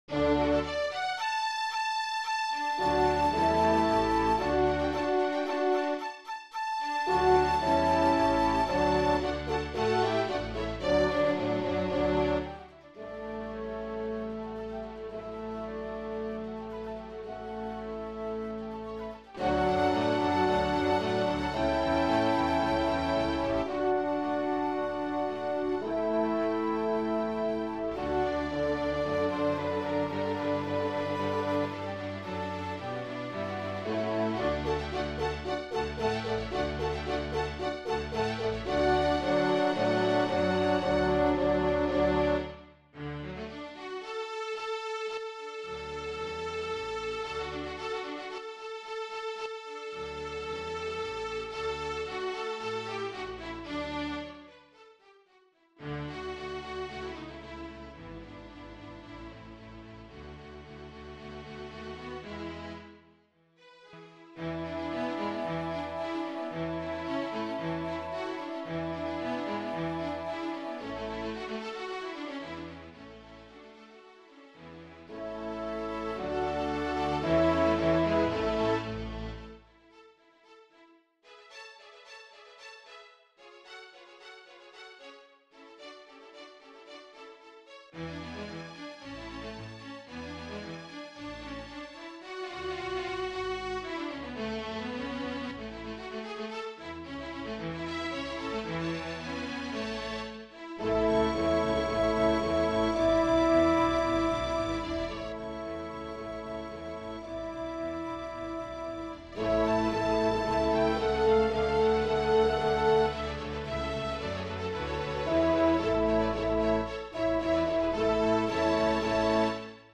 for orchestra
Dittersdorf Double Bass Concerto I in D MIDI.mp3